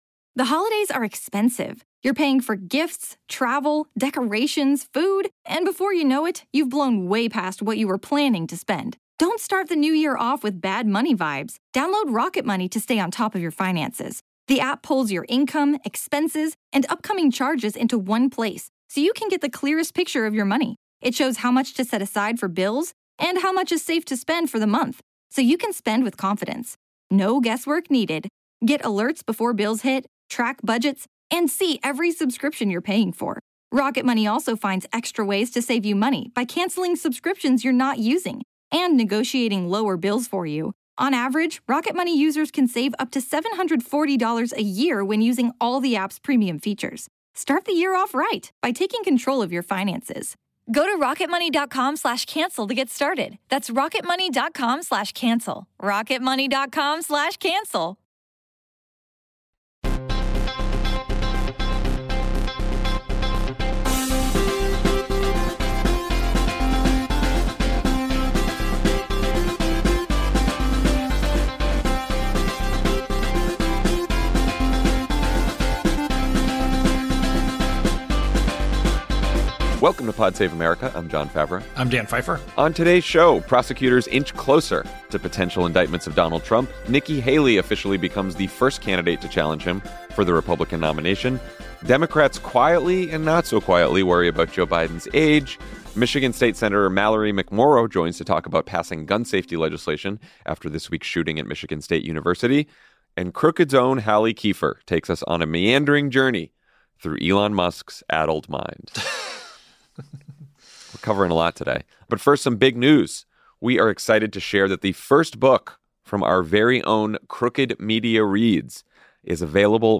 Democrats quietly and not-so-quietly worry about Joe Biden’s age. Michigan State Senator Mallory McMorrow joins to talk about gun safety legislation after the shooting at Michigan State.